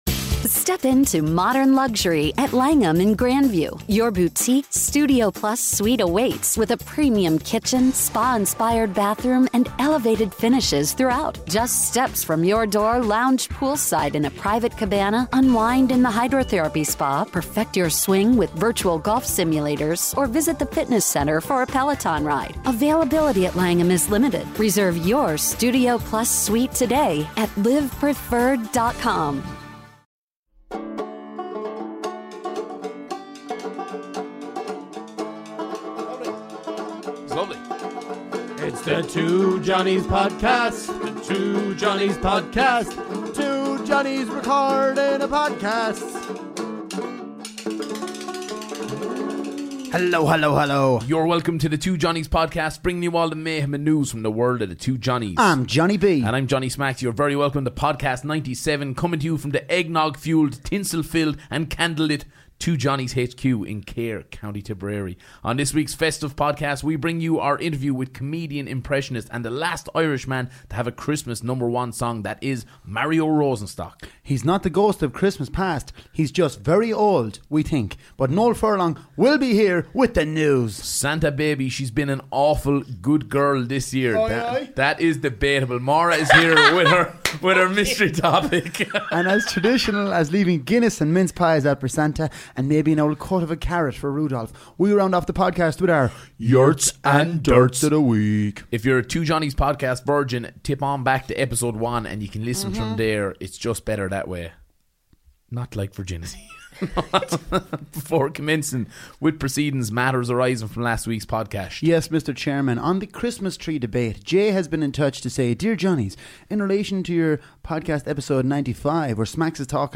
Ireland's favourite comedy duo tackle the big issues, this week:
The boys interview outstanding comedian, impressionist & the last Irish act to have a christmas no.1 Mario Rosenstock.